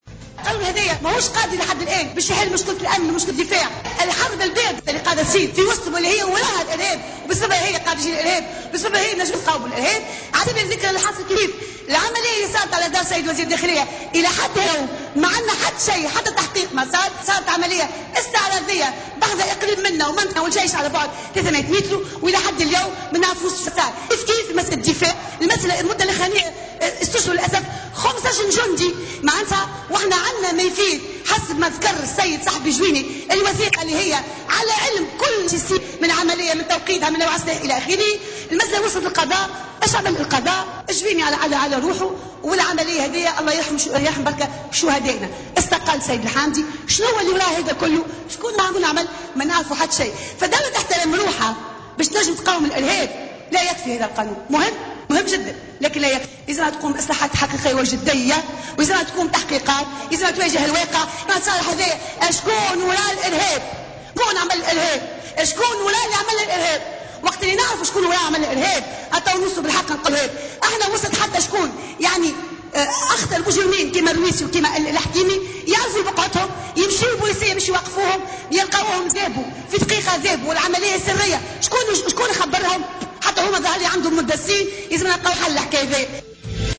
أكدت النائبة بالمجلس الوطني التأسيسي سامية عبو في مداخلة لها بالجلسة العامة للنظر في مشروع قانون مكافحة الإرهاب أن هذا القانون مهم لكن غير كافي وغير قادر على القضاء على الإرهاب في تونس.